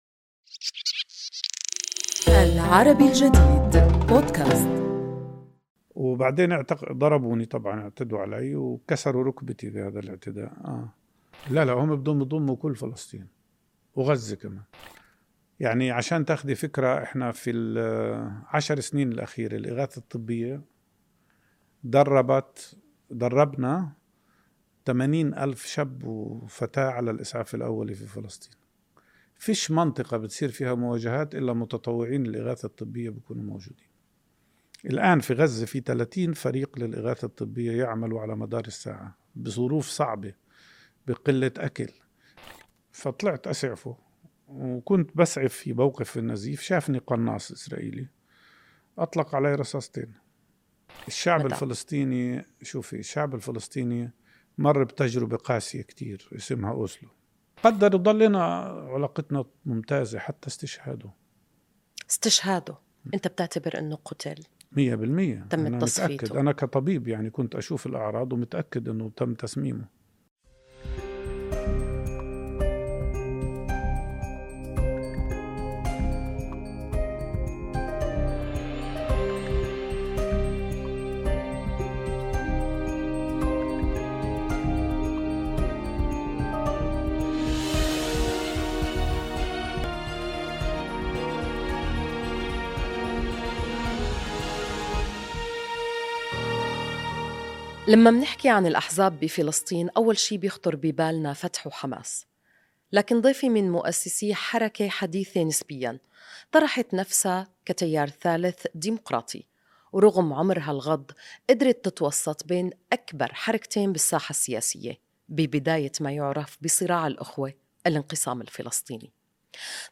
الأمين العام لحركة المبادرة الفلسطينية، الدكتور مصطفى البرغوثي، ضيف بودكاست في "الظل".